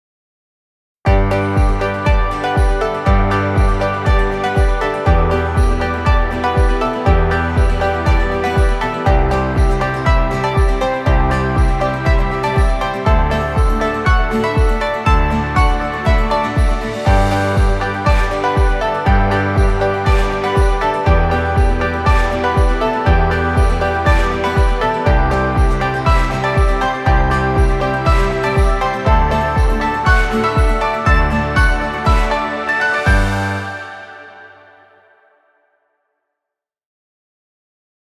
upbeat corporate track.